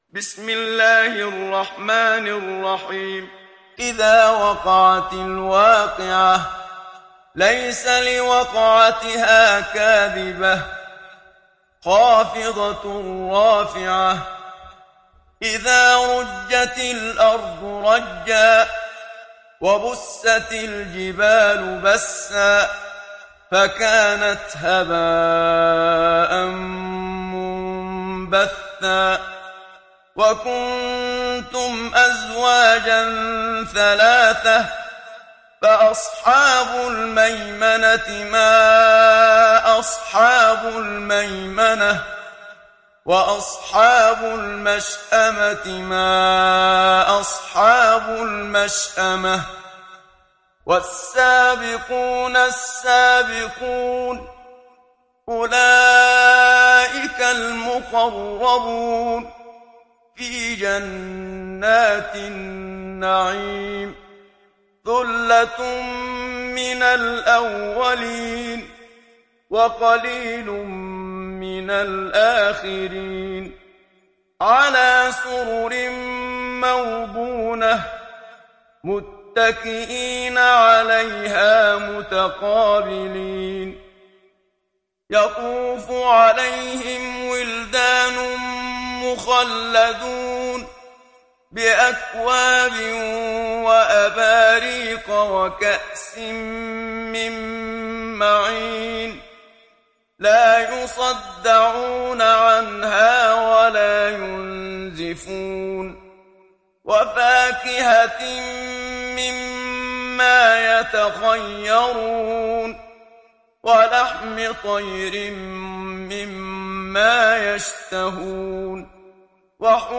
تحميل سورة الواقعة mp3 بصوت محمد صديق المنشاوي برواية حفص عن عاصم, تحميل استماع القرآن الكريم على الجوال mp3 كاملا بروابط مباشرة وسريعة